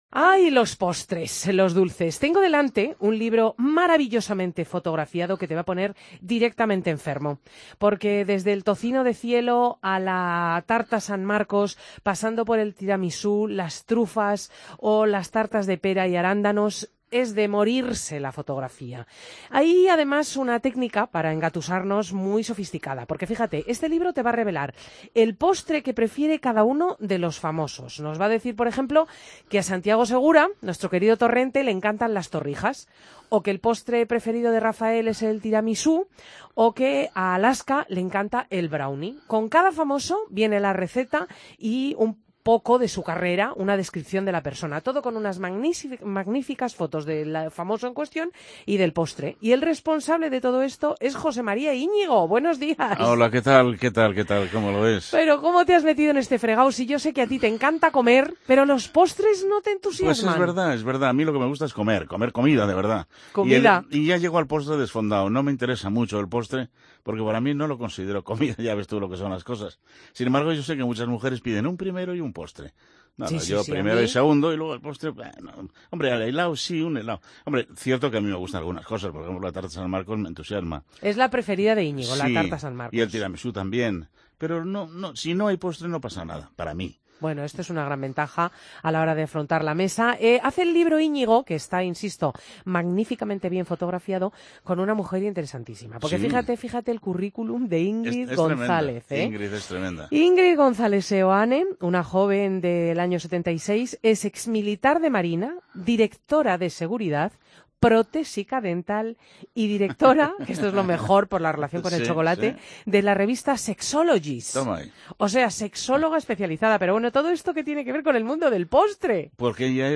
En 2015, el programa 'Fin de Semana' de la Cadena COPE entrevistó al mítico presentador de TVE.
Entrevista a Jose María Iñigo en Fin de Semana